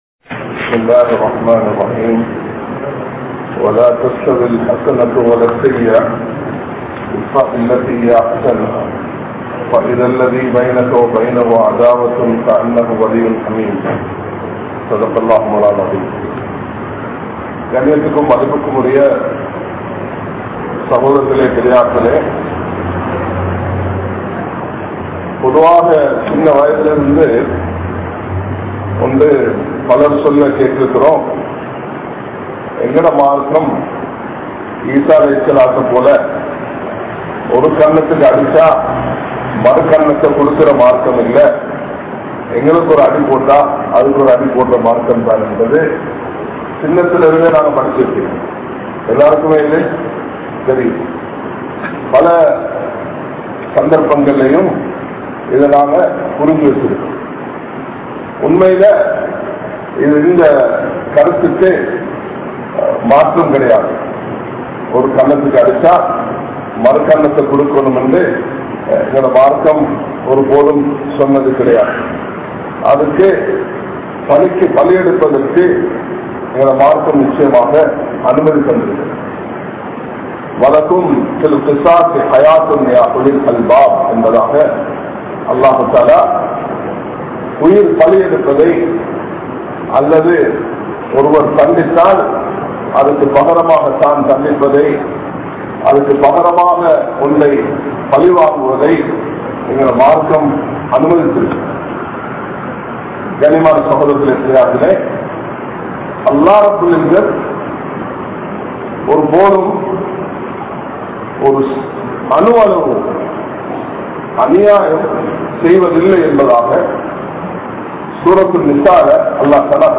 Muslimkal Aniyaayak Kaararhal Illai(முஸ்லிம்கள் அநியாயக் காரர்கள் இல்லை) | Audio Bayans | All Ceylon Muslim Youth Community | Addalaichenai